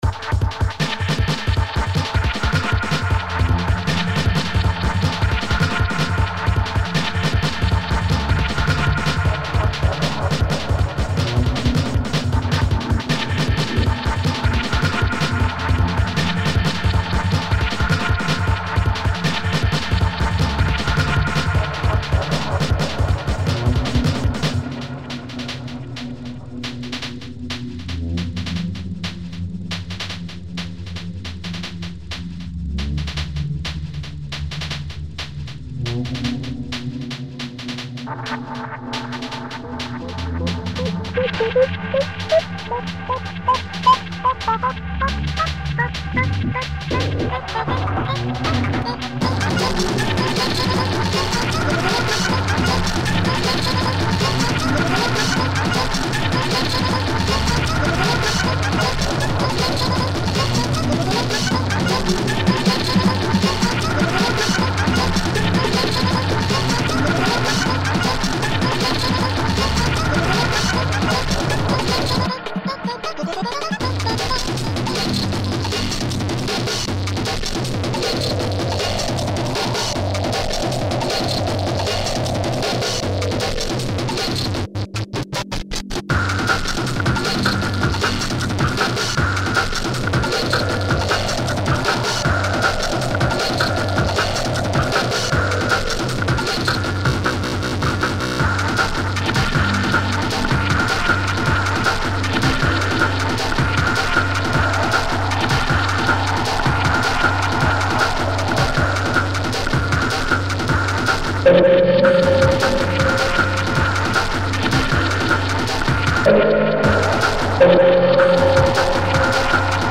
ambient d&b